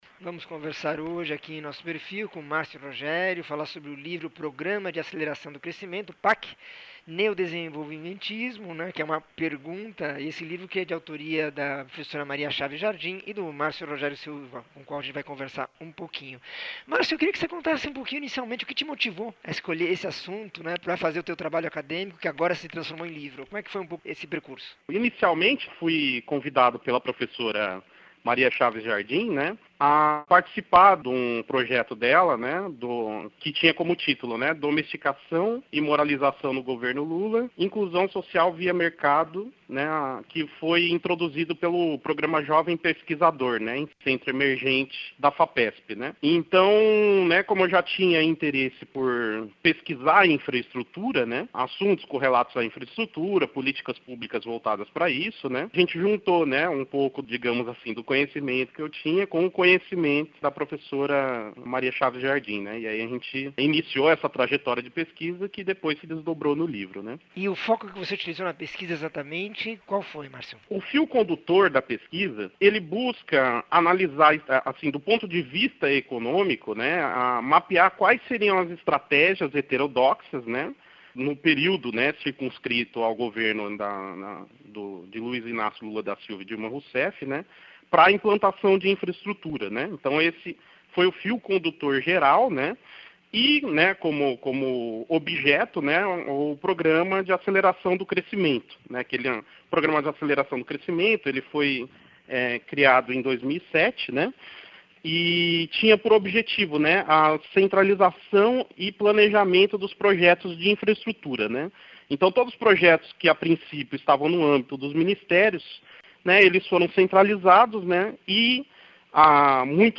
entrevista 2507